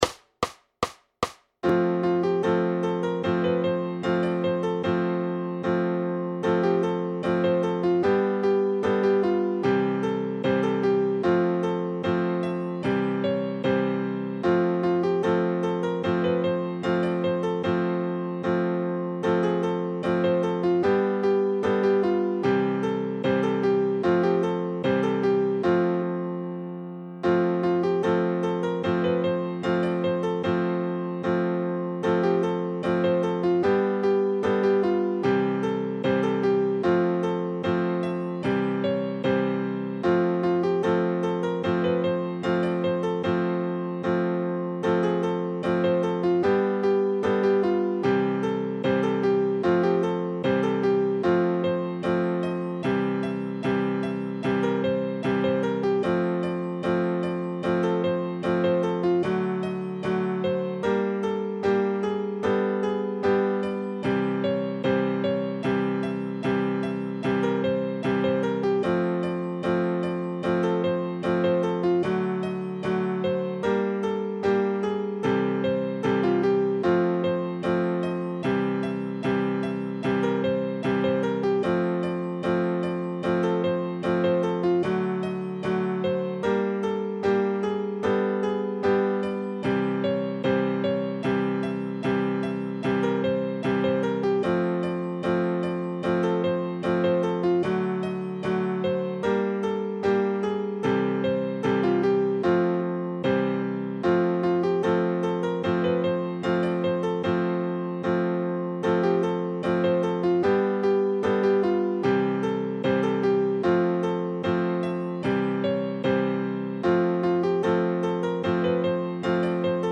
Noty na snadný klavír.
Hudební žánr Ragtime